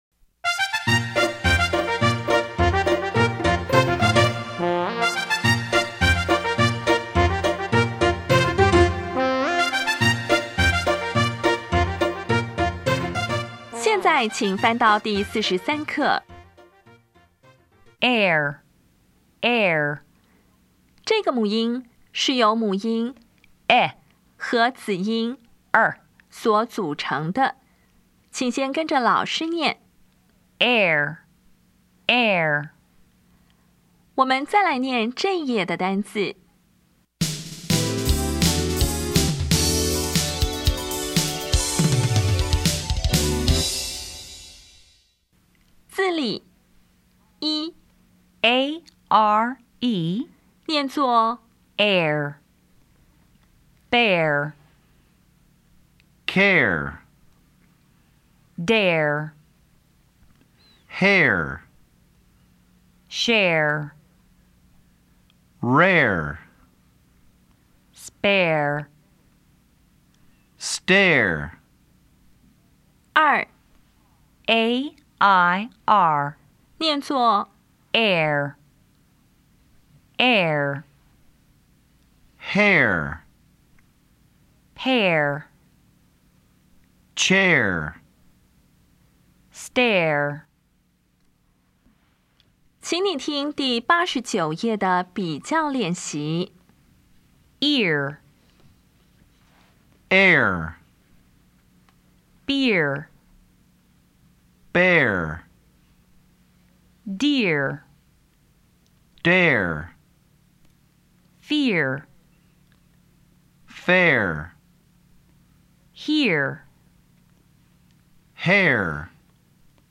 当前位置：Home 英语教材 KK 音标发音 母音部分-3: 双母音 [ɛr]
音标讲解第四十三课
比较 [ɪr] 与 [ɛr]
bear  [bɛr]  熊